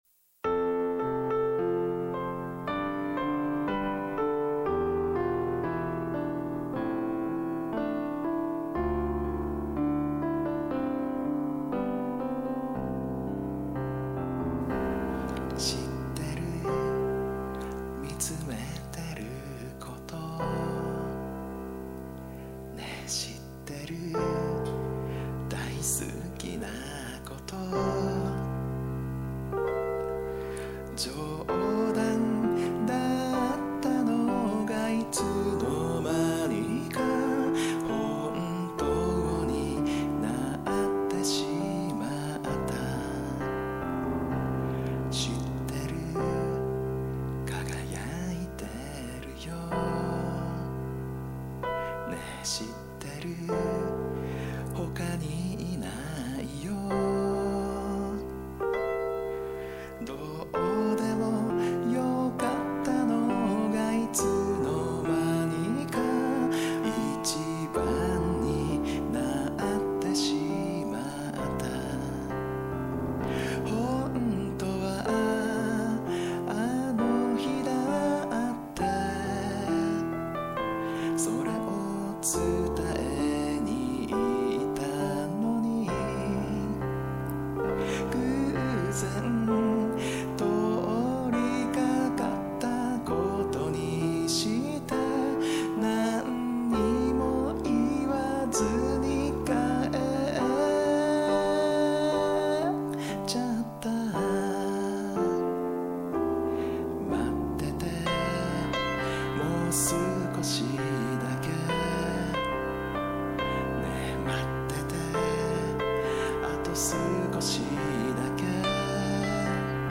Memo : たまにはピアノ弾き語り。